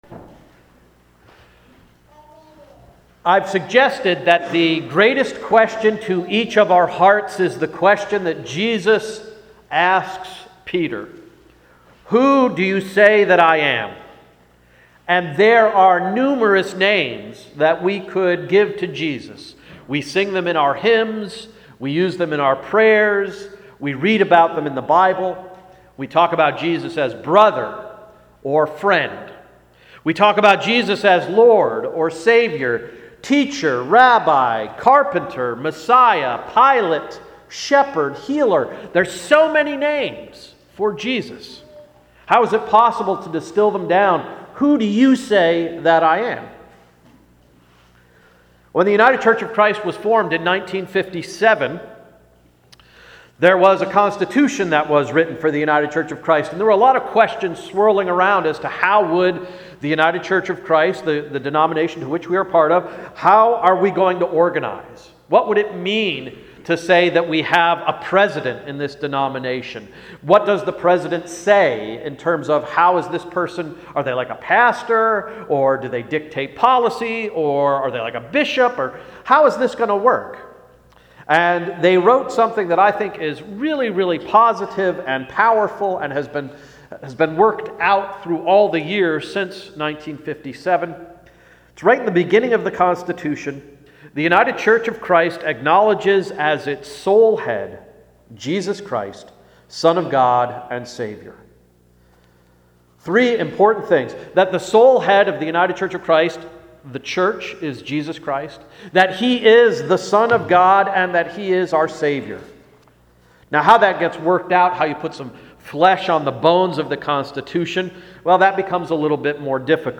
Sermon of September 16, 2012–“The Greatest Question”